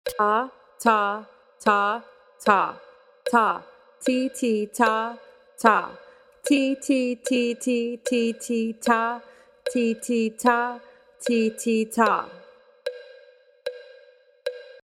For example, when counting a quarter note, you will say “ta” or when speaking a series of 16th notes you will read “tika-tika”.
In this example, the musician speaks a very simple rhythm using the Kodály Method.